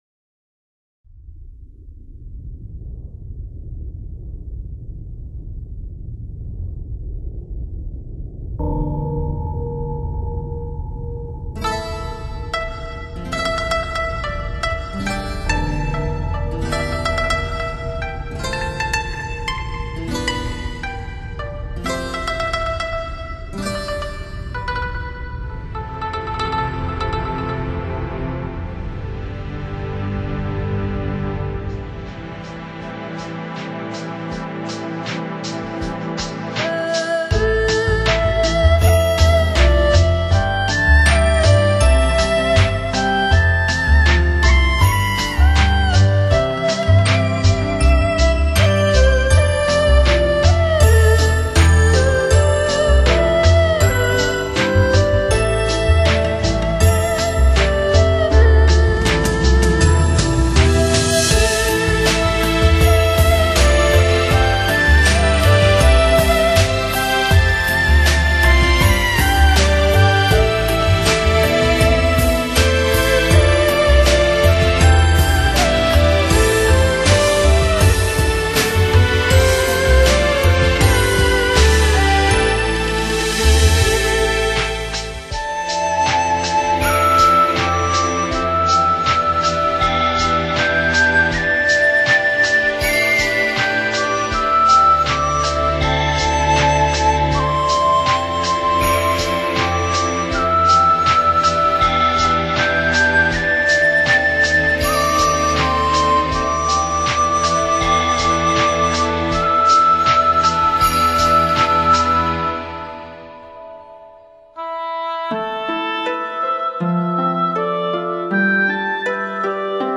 二胡的演奏可谓无可挑剔，加上婉转悠扬的旋律，如此水准不得不令人赞叹同人的实力。